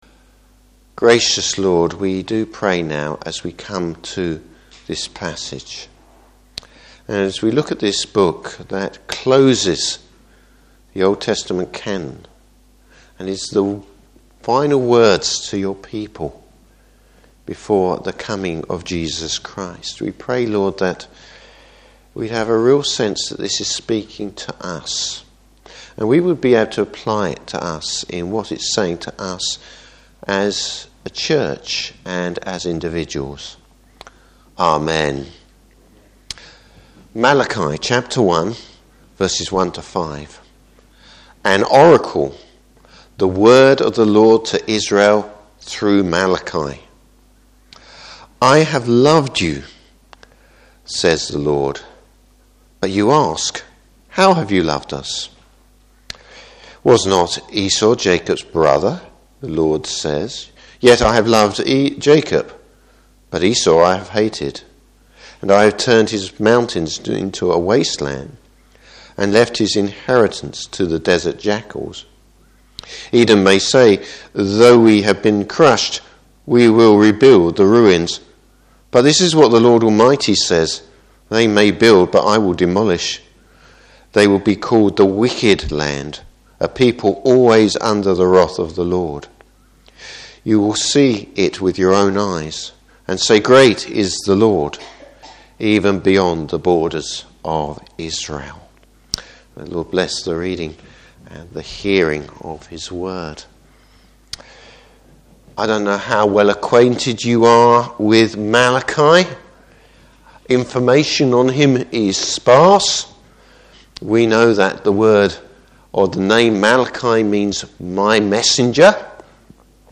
Service Type: Morning Service The difference between apathy towards God and the rejection of God.